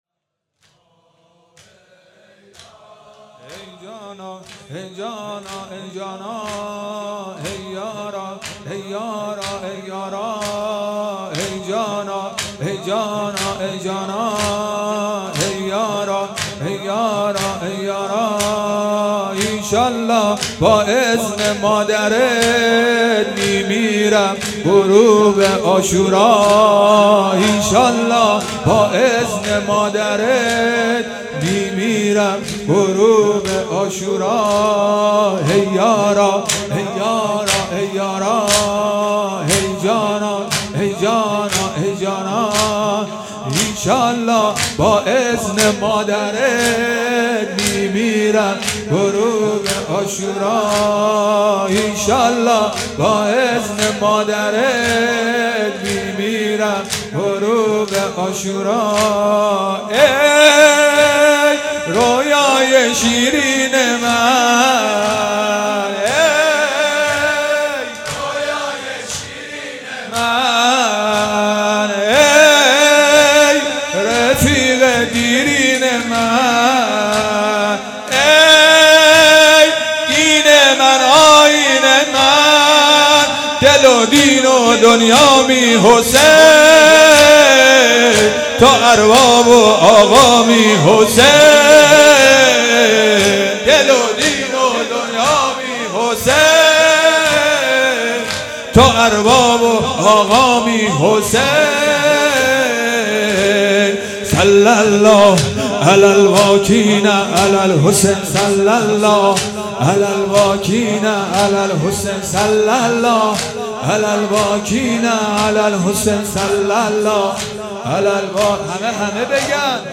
شب ششم محرم الحرام 1441